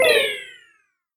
crash.727b901e4b2f10654d47.mp3